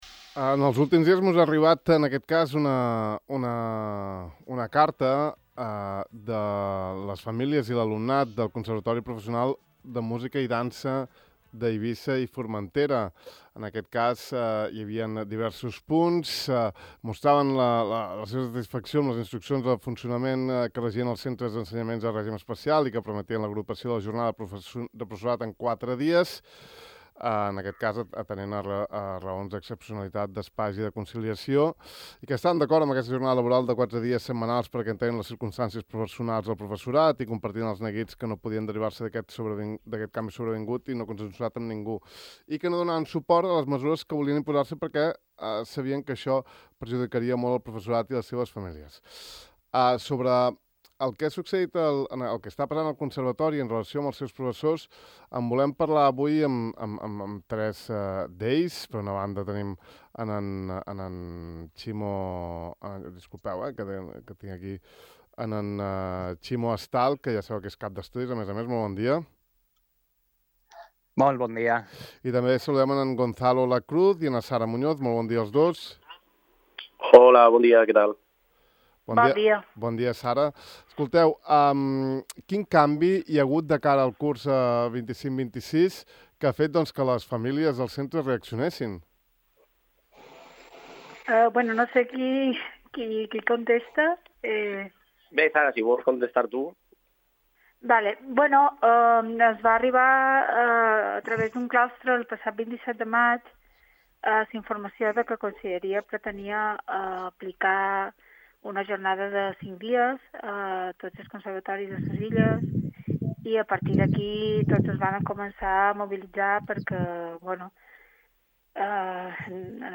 Podeu escoltar l’entrevista amb ells tres aquí: